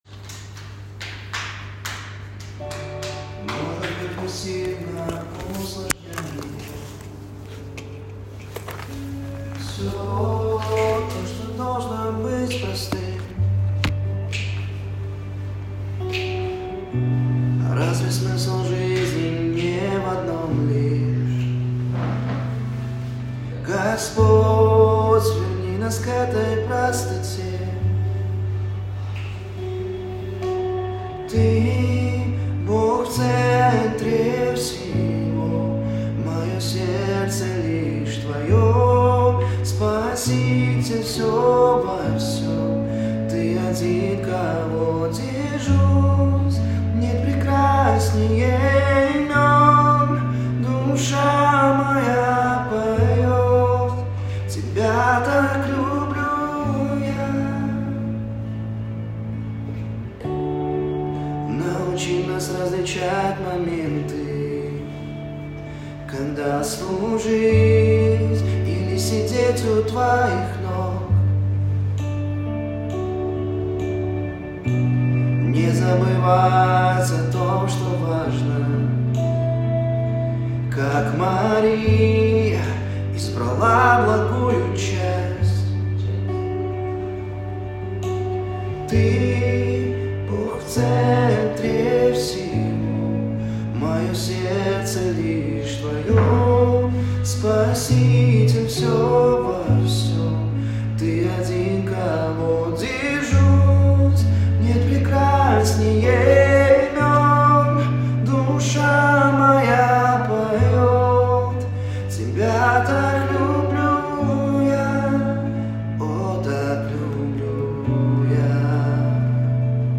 517 просмотров 519 прослушиваний 11 скачиваний BPM: 66